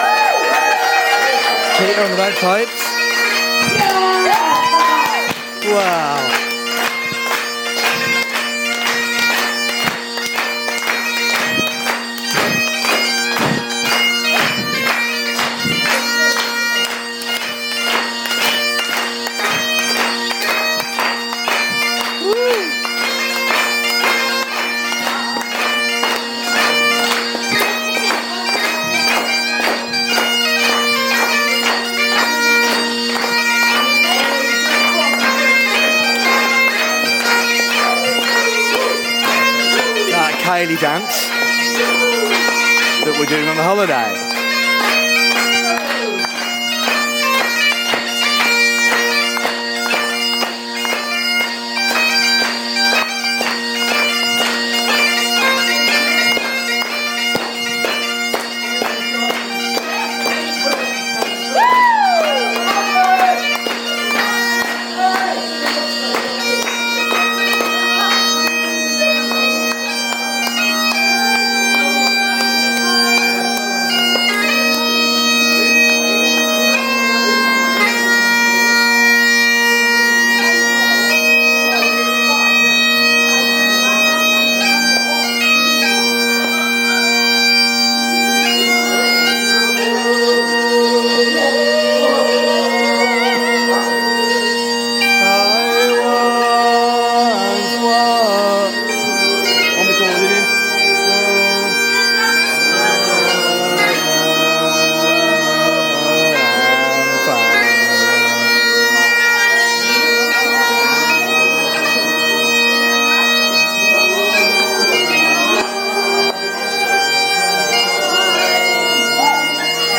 Bagpipes at dance